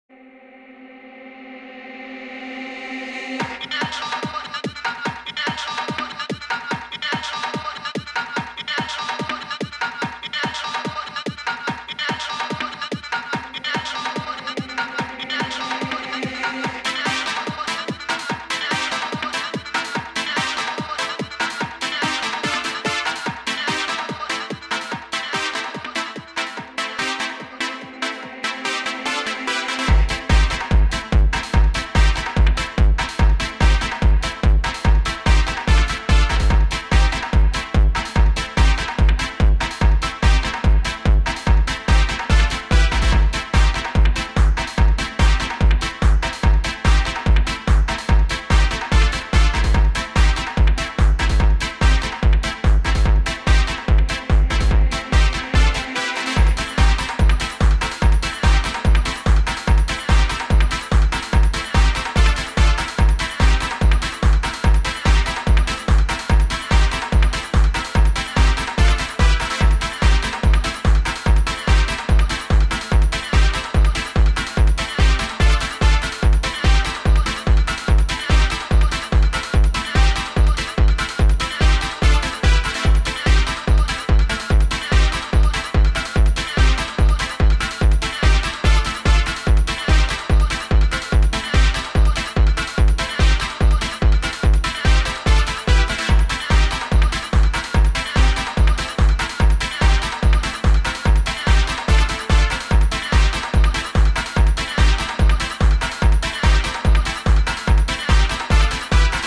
Auf jeden Fall schon mal ganz FETT ! ! !